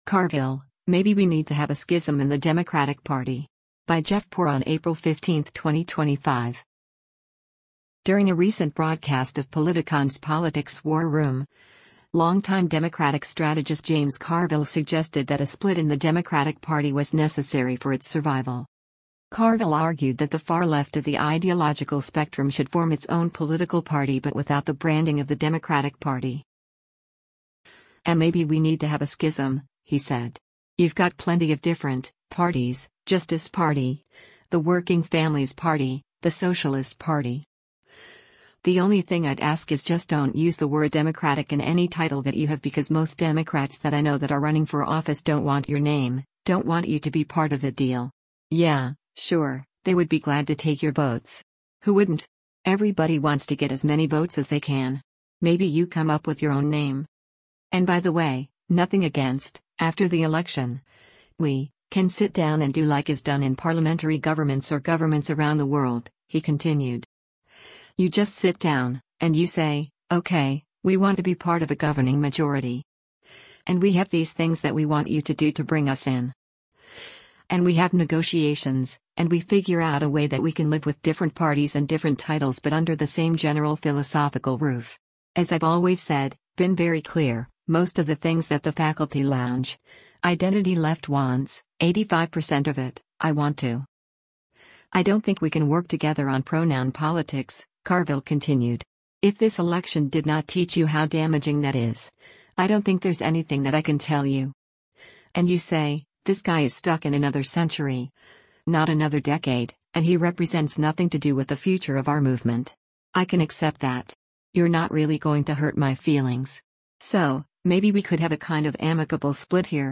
During a recent broadcast of Politicon’s “Politics War Room,” long-time Democratic strategist James Carville suggested that a split in the Democratic Party was necessary for its survival.